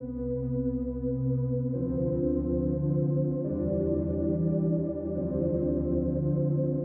Tag: 140 bpm Chill Out Loops Synth Loops 1.15 MB wav Key : Unknown